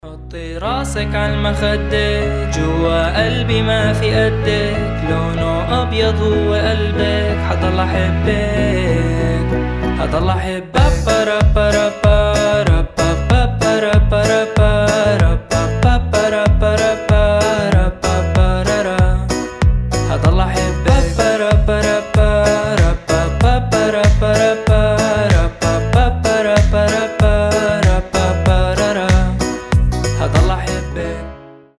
• Качество: 320, Stereo
поп
мужской вокал
арабские